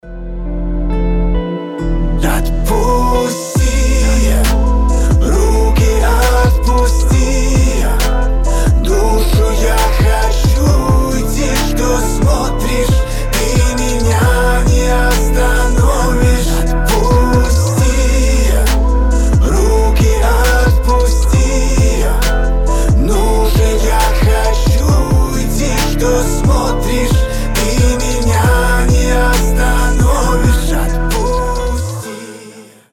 • Качество: 320, Stereo
лирика
грустные
печальные